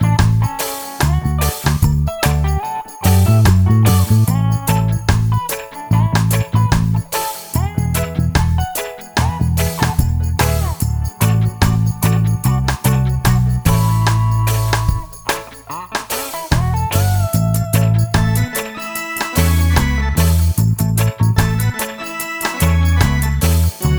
Minus Solo Guitar And Organ Reggae 3:04 Buy £1.50